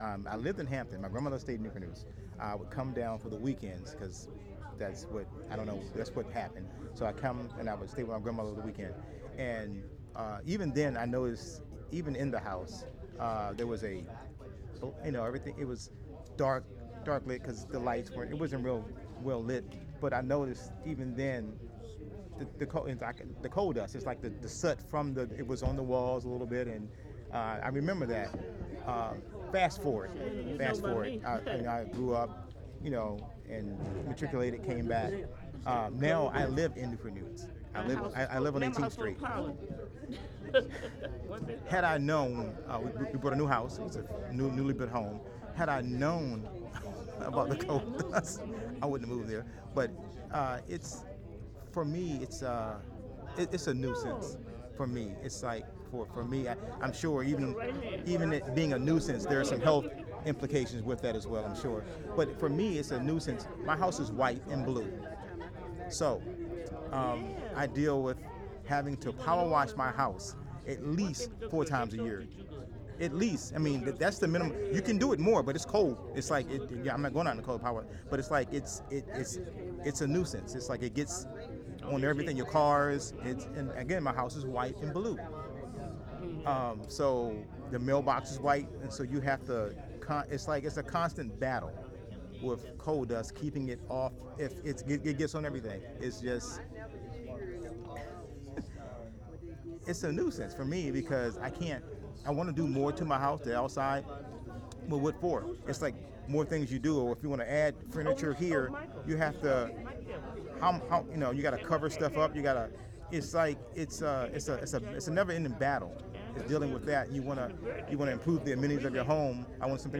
This is a short interview with an anonymous attendee of the "The Air We Breathe" event hosted by EmPower All at Zion Baptist Church in collaboration with the Repair Lab. In the brief interview, this person describes his memories of coal dust as a child visiting Newport News, his efforts to learn more about the dust as an adult living in Newport News and the discomfort and expense the dust has caused him.
Note: This is an informal interview at a public event, so there is considerable background sound, as well as brief exchanges between the interviewee, his wife and another event attendee.